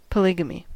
Ääntäminen
US : IPA : [pə.ˈlɪɡ.ə.mi]